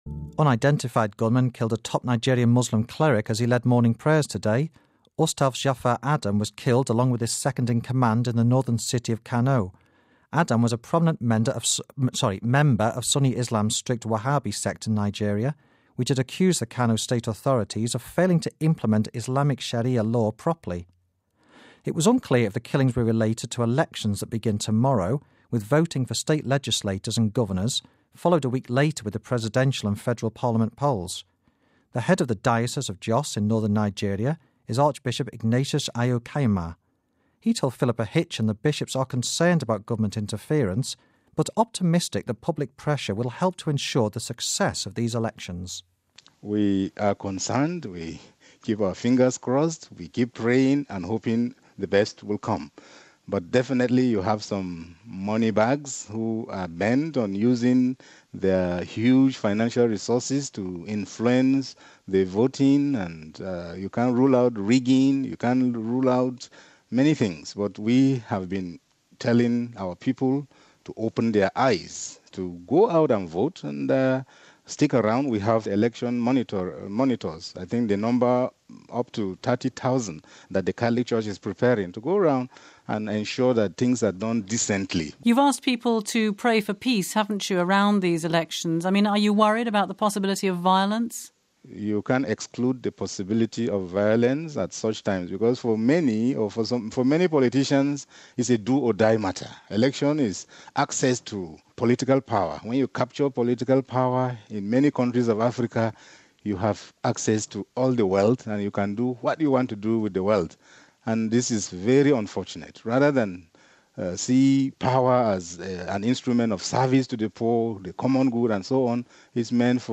(13 Apr '07 - RV) - A leading Muslim cleric was gunned down today as he led morning prayers in the Nigerian city of Kano, jsut one week before elections are set to take place. Archbishop Ignatius Ayau Kaigama, from the Diocese of Joss in northern Nigeria, speaks to us about the situation...